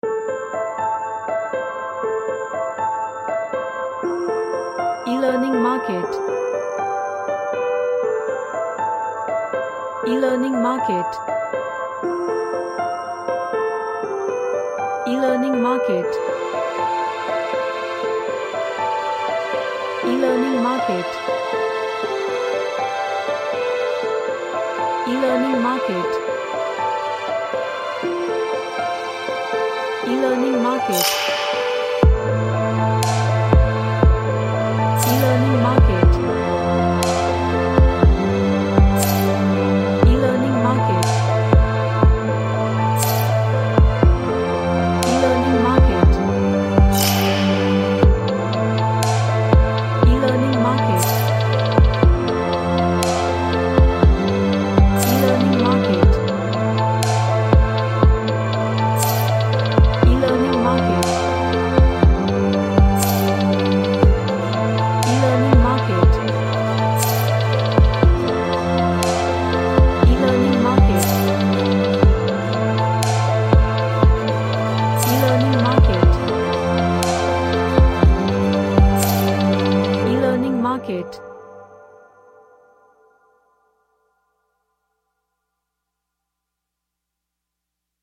An emotional cinematic/ Orchestral track
Emotional